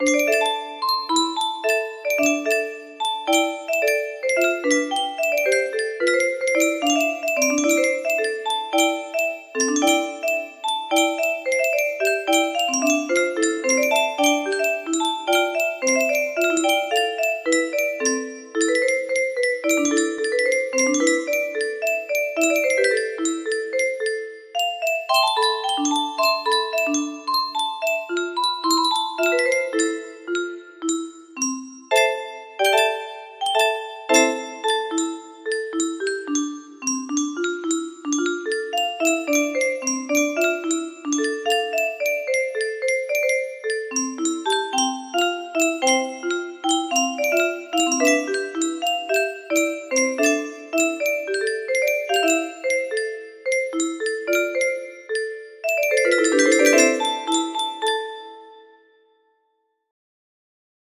scarab 21-1 music box melody